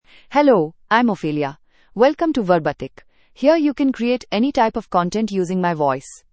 Ophelia — Female English (India) AI Voice | TTS, Voice Cloning & Video | Verbatik AI
Ophelia is a female AI voice for English (India).
Voice sample
Listen to Ophelia's female English voice.
Female
Ophelia delivers clear pronunciation with authentic India English intonation, making your content sound professionally produced.